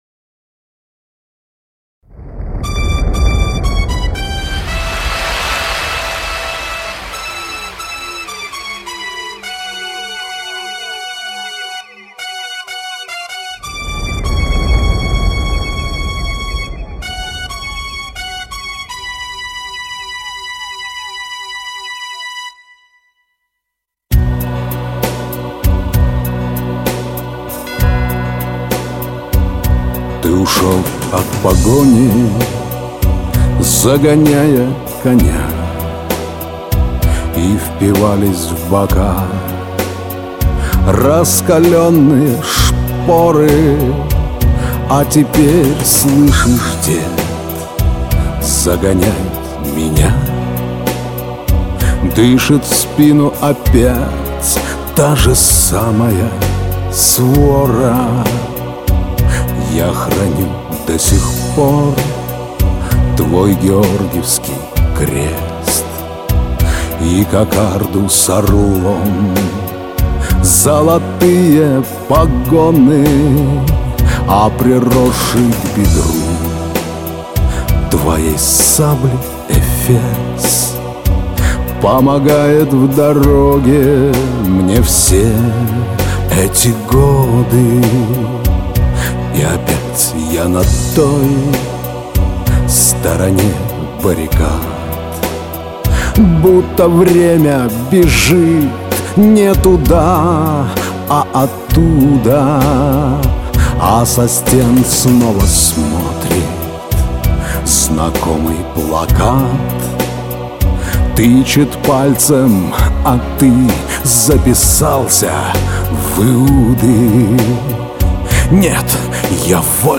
Белогвардейский_романс_-_Господа_офицеры_не_снимайте_погоны